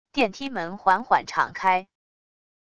电梯门缓缓敞开wav音频